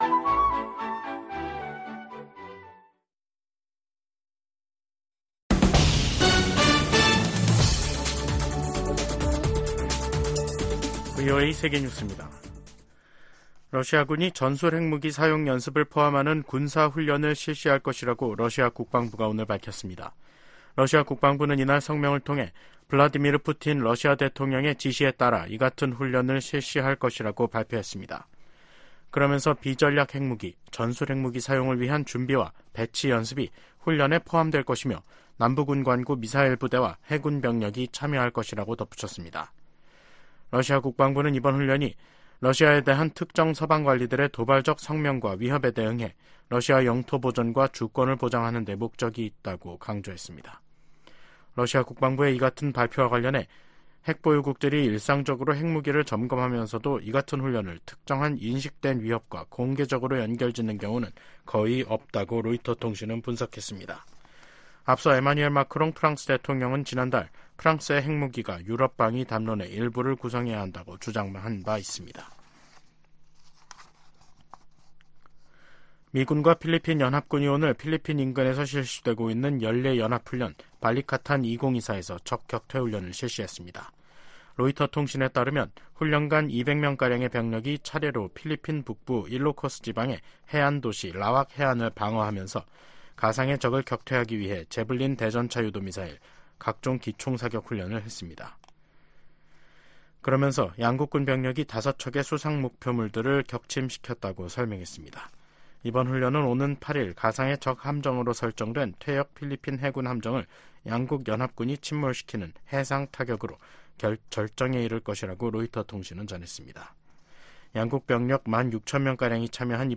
VOA 한국어 간판 뉴스 프로그램 '뉴스 투데이', 2024년 5월 6일 2부 방송입니다. 미국과 일본, 호주 국방장관들이 북러 군사협력 심화와 북한의 반복적인 미사일 발사를 강력히 규탄했습니다. 북한이 아무런 댓가없이 러시아에 무기를 지원하는 것은 아니라고 미국 관리가 말했습니다. 북러 무기 거래가 명백한 유엔 안보리 결의 위반이라는 점도 지적했습니다.